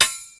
terumet_saw_fail.ogg